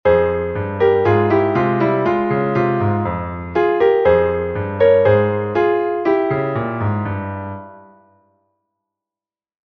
Meter: 8.7.8.7
Key: f minor